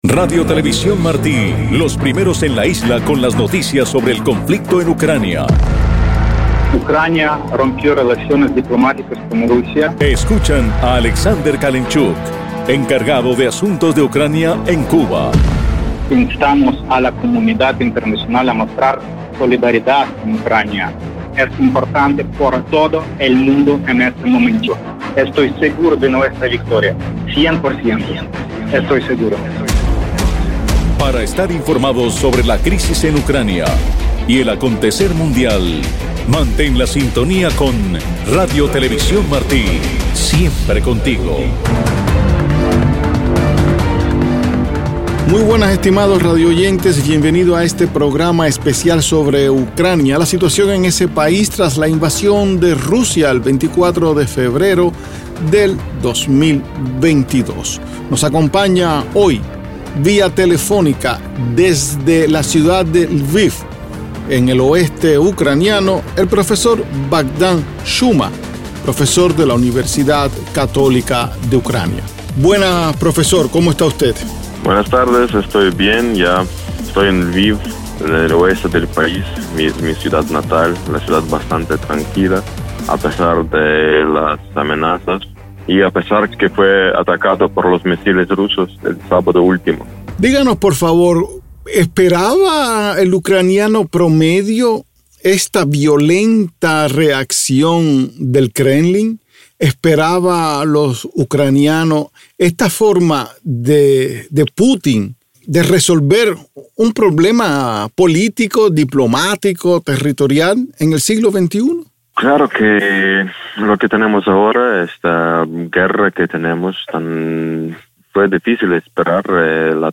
Testimonios de la Guerra en Ucrania: entrevista